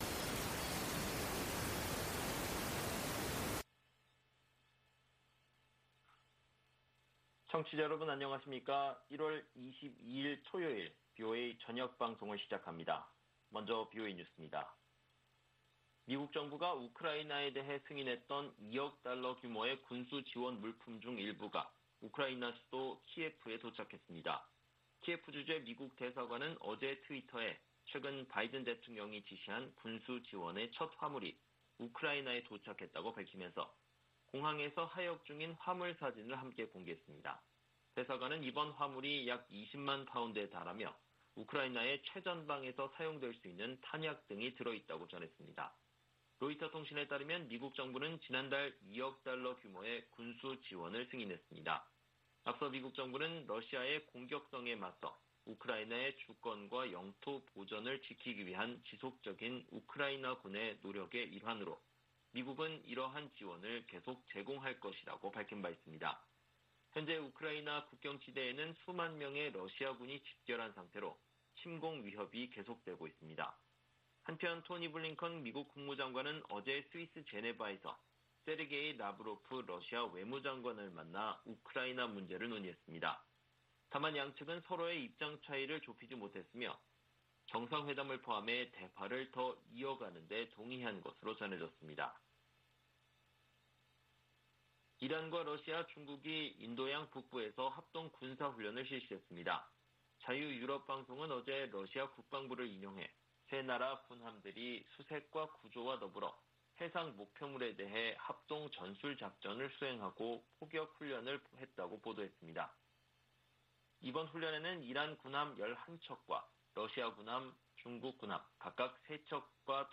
VOA 한국어 방송의 토요일 오후 프로그램 1부입니다.